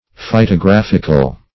Search Result for " phytographical" : The Collaborative International Dictionary of English v.0.48: Phytographical \Phy`to*graph"ic*al\, a. [Cf. F. phytographique.] Of or pertaining to phytography.
phytographical.mp3